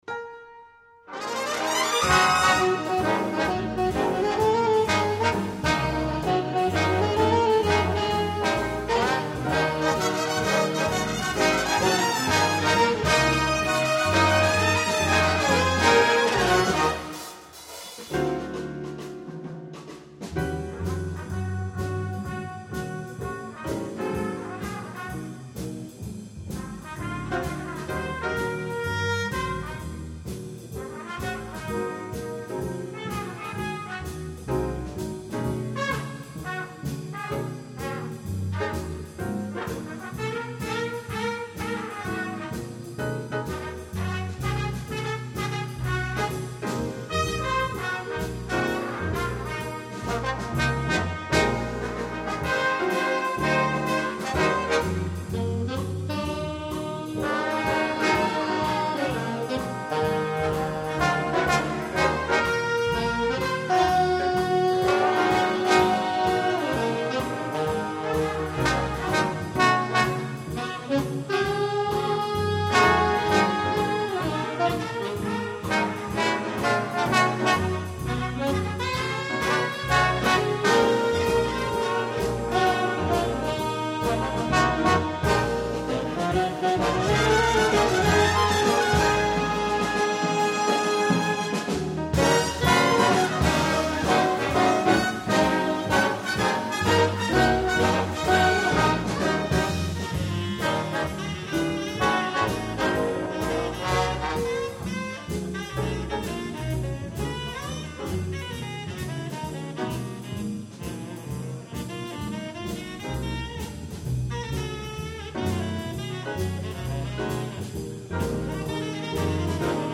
Jazz
As a dentist, he's specialised in healing the 'jazz blowers': and you can sometimes meet several famous trumpet, trombone or sax players in his waiting room.
and you can go and listen to his own big band in various gigs in the New York area.
I just uploaded a big, bold and terrific example